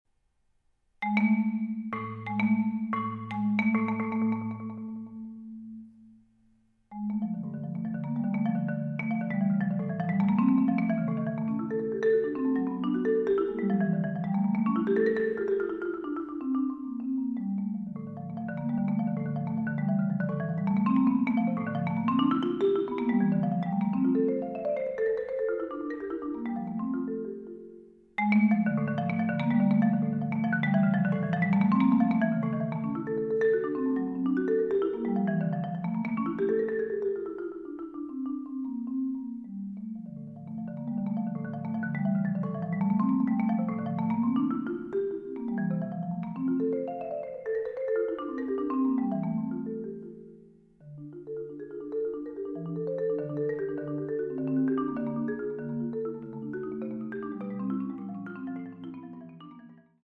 Genre: Marimba (4-mallet)
Marimba (5-octave)